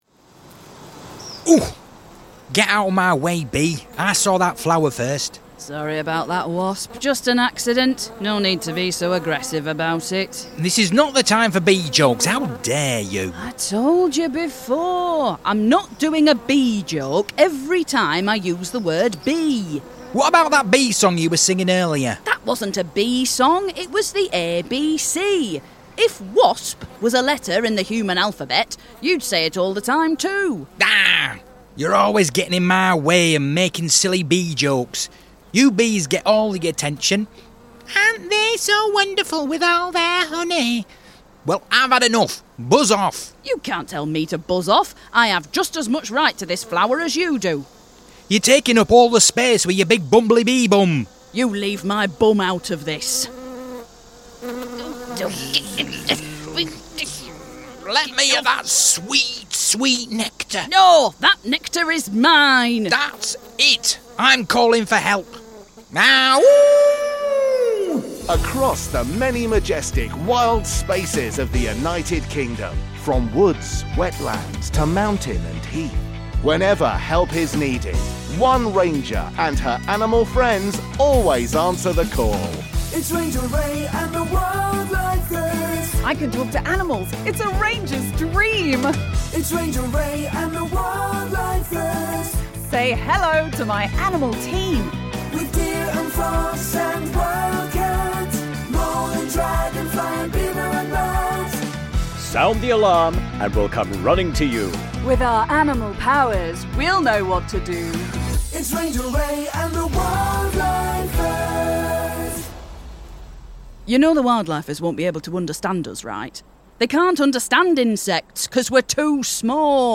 All other characters played by members of the ensemble.